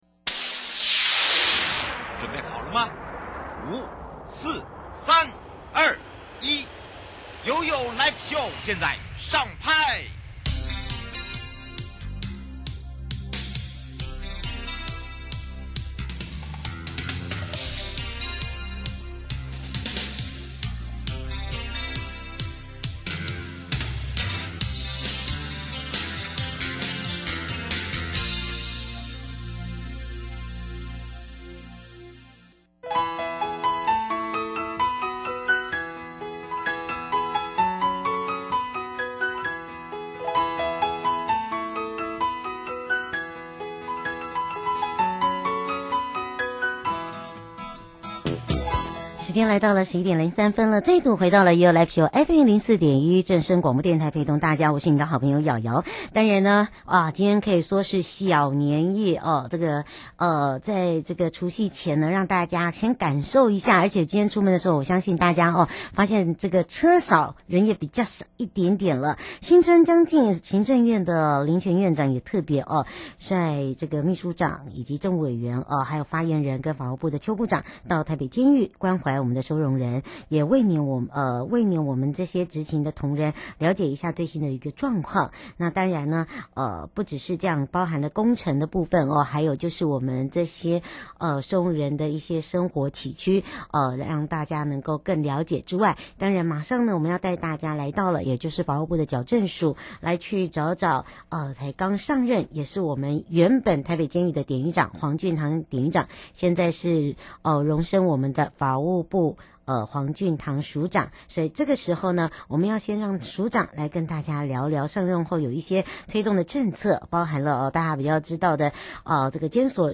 受訪者： 台北地檢許祥珍主任檢察官 法務部矯正署 黃俊棠署長 節目內容： 如果被警方查獲或被舉報第一次施用一級或二級毒品，通常檢察官慧如何處置？ 法務部矯正署署長說明上任後八大獄政改革目標方向？